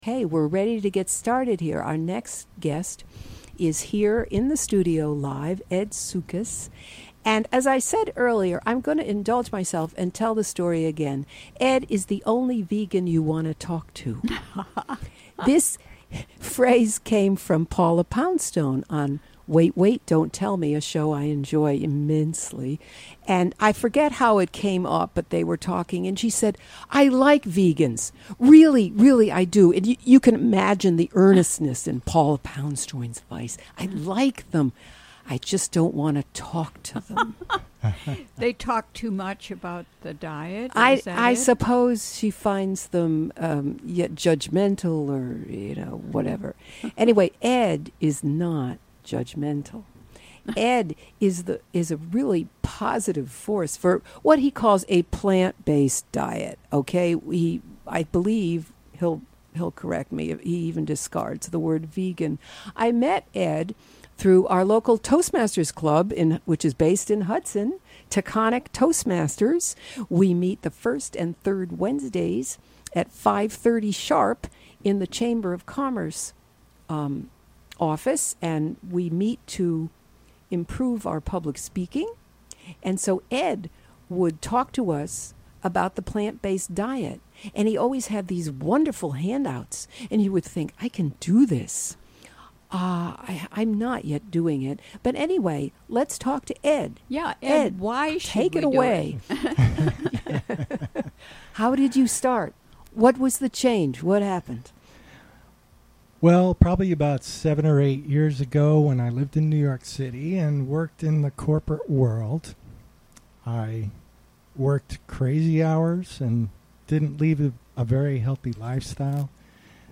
6pm The show features local news, interviews with comm...